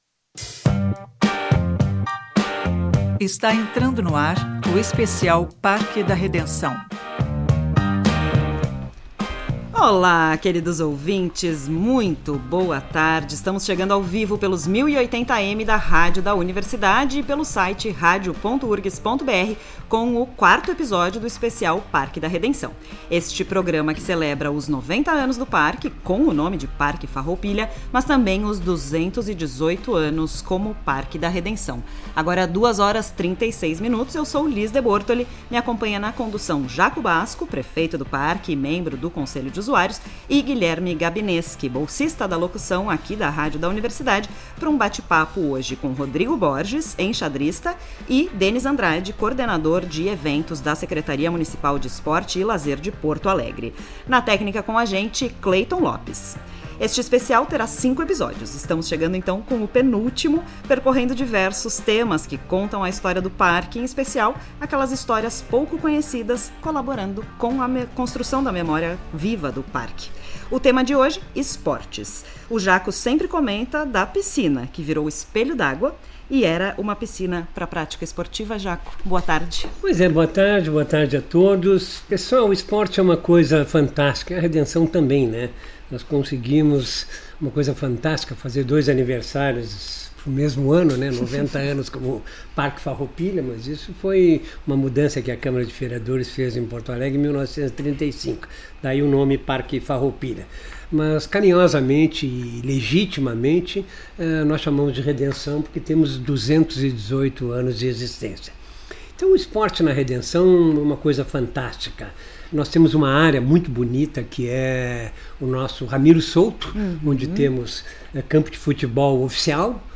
ESPECIAL REDENÇÃO AO-VIVO 12-12-25.mp3